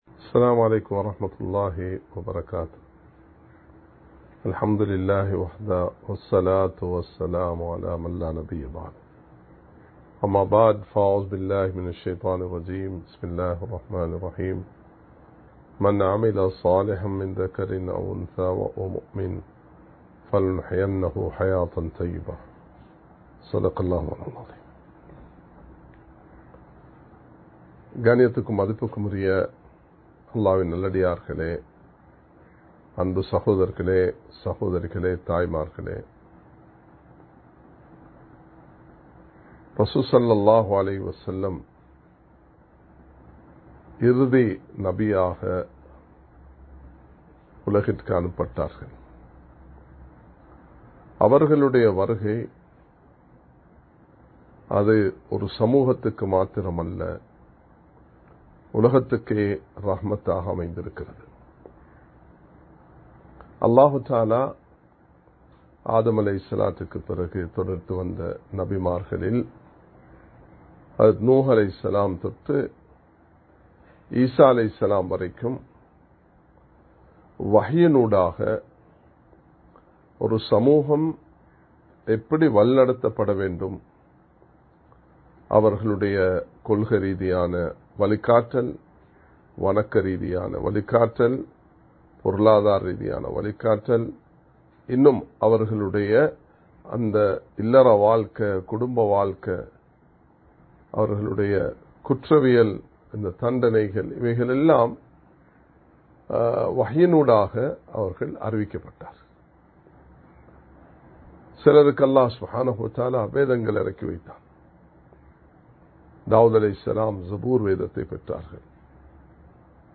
இஸ்லாமிய பார்வையில் பெண்கள் (பகுதி 01) | Audio Bayans | All Ceylon Muslim Youth Community | Addalaichenai
Live Stream